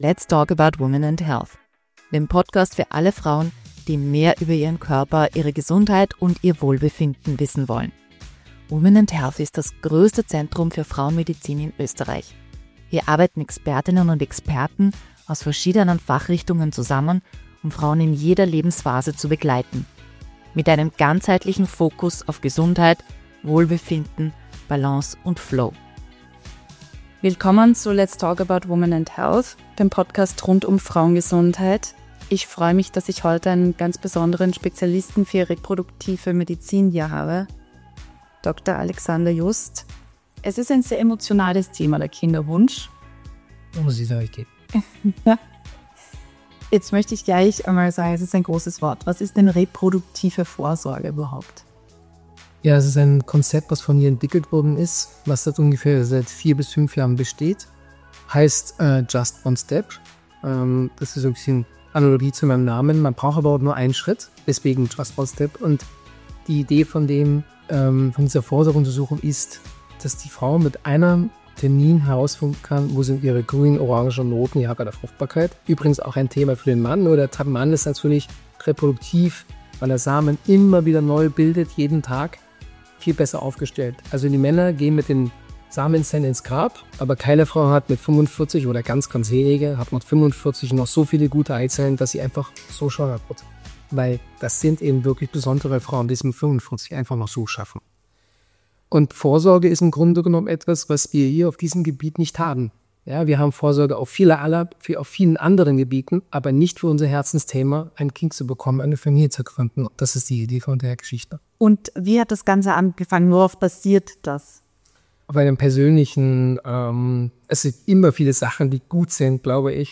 Ein aufrüttelnder und motivierender Talk für alle, die heute schon an morgen denken wollen.